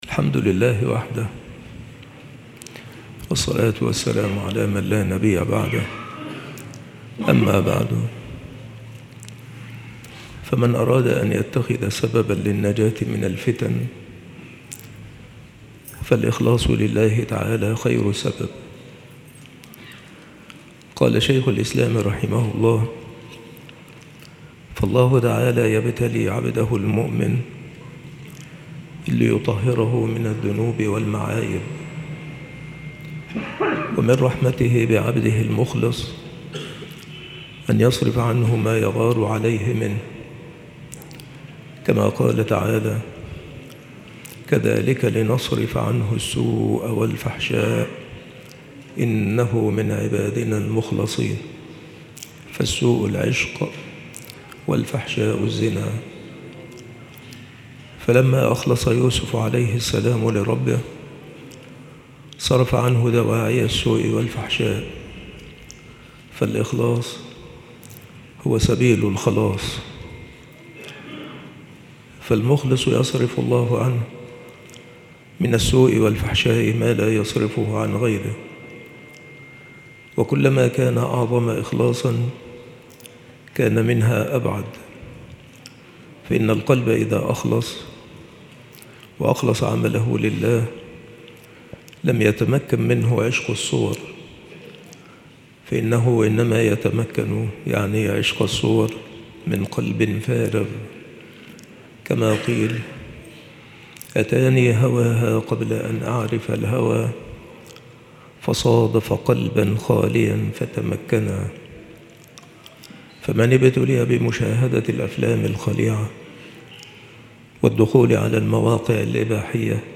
مواعظ وتذكير
مكان إلقاء هذه المحاضرة بالمسجد الشرقي - سبك الأحد - أشمون - محافظة المنوفية - مصر